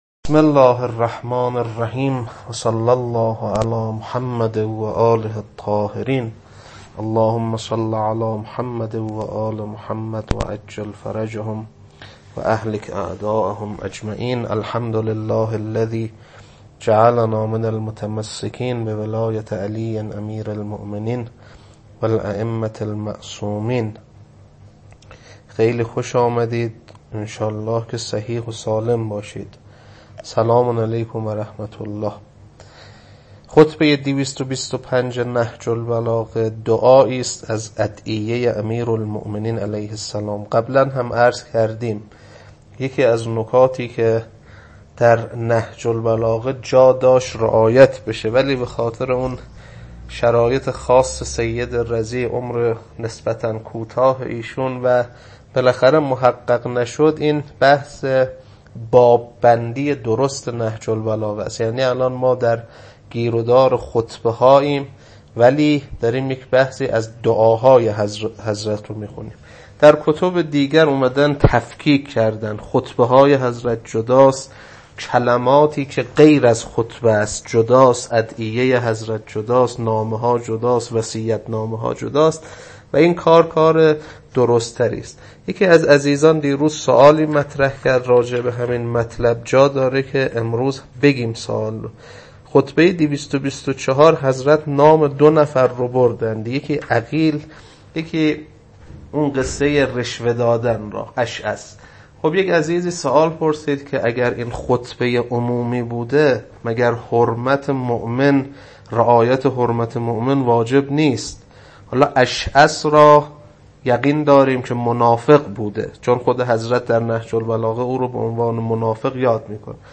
خطبه 225.mp3